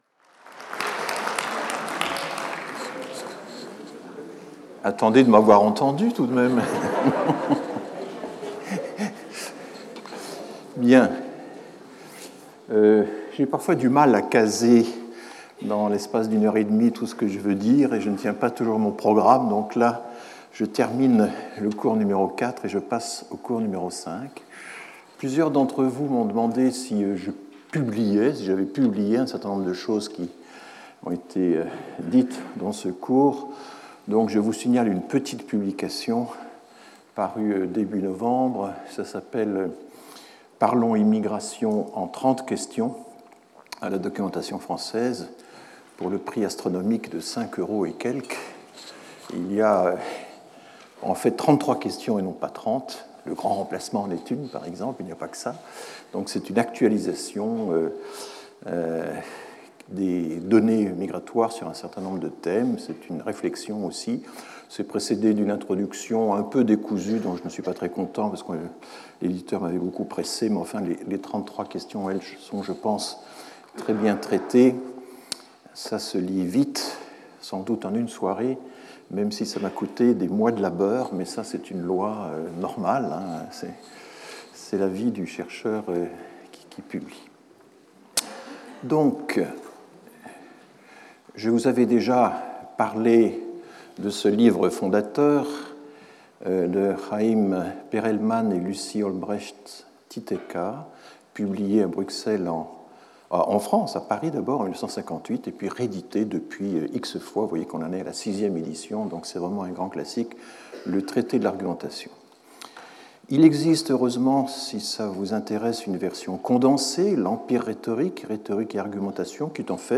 Limits of metaphor (and, even more so, megaphor) : it doesn't explain, it " transplicates ", displacing the objects of discourse and creating the illusion of explanation. Speaker(s) François Héran Professor at the Collège de France Events Previous Lecture 29 Oct 2021 10:30 to 12:00 François Héran By way of introduction : migration news (2018-2021) Lecture 5 Nov 2021 10:30 to 12:00 François Héran Numbers in debate.